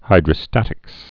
(hīdrə-stătĭks)